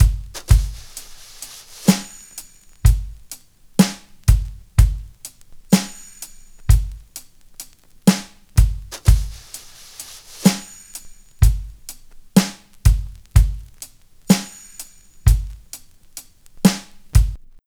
56BRUSHBT1-L.wav